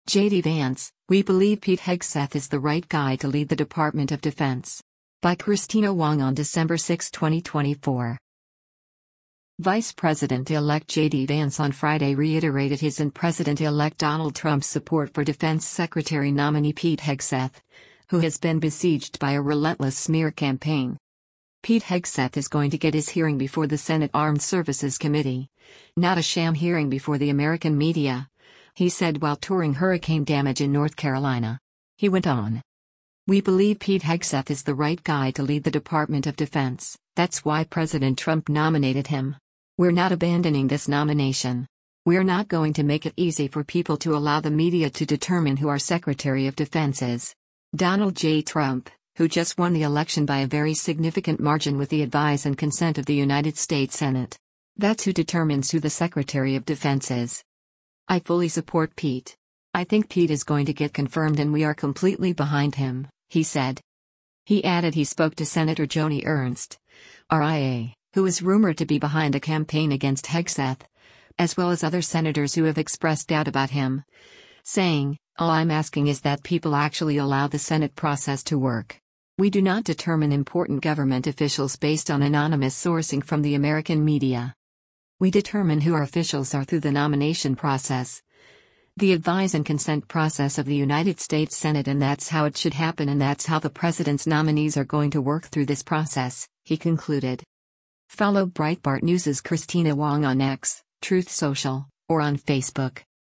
“Pete Hegseth is going to get his hearing before the Senate Armed Services Committee, not a sham hearing before the American media,” he said while touring hurricane damage in North Carolina.